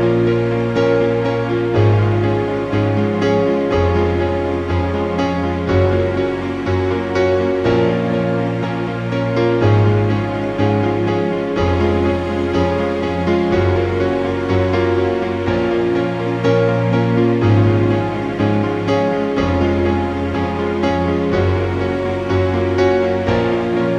Intro Cut Down Pop (2010s) 3:36 Buy £1.50